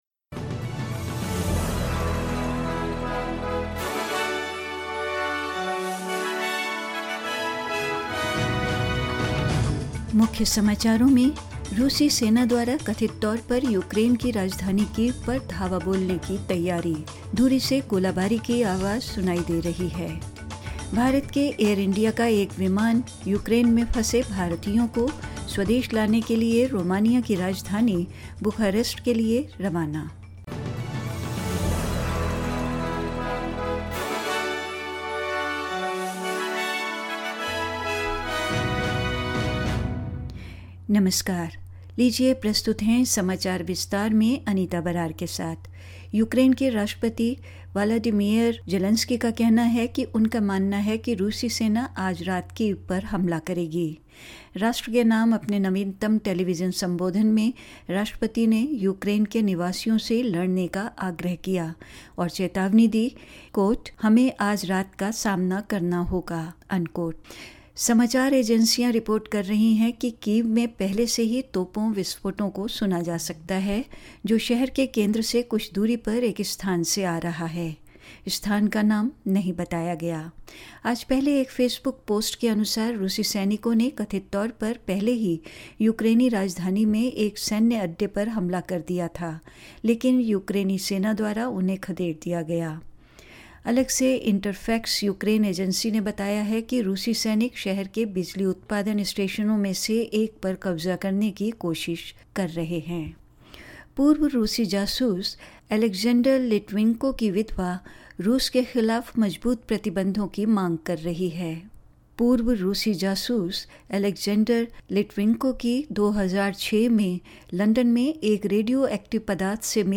In this latest SBS Hindi bulletin: Russian forces are reportedly preparing to storm the Ukrainian capital of Kyiv, as shelling can be heard in the distance; The widow of a poisoned Russian spy calls for even tougher sanctions against Putin; A special flight of Air India to Bucharest to evacuate stranded Indians and more news.